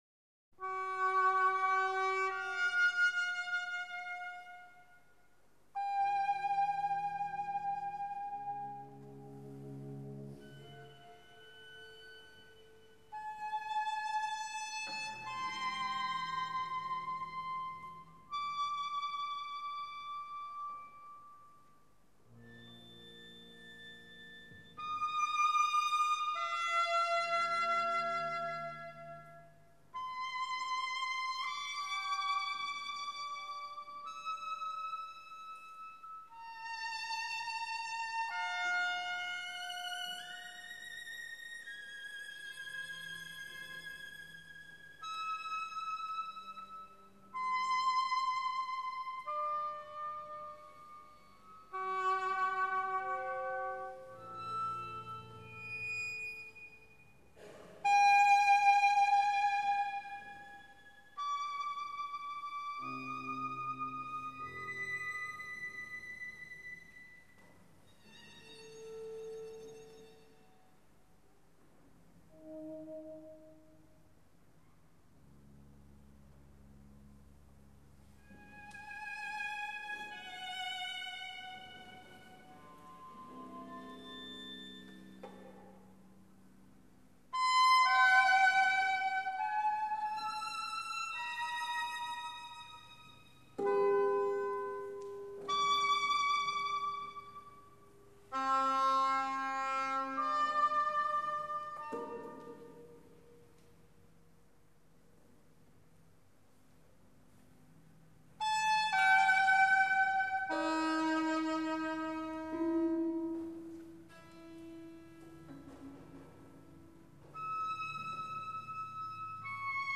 maderna-concerto-n-1-per-oboe-e-orch-da-camera.mp3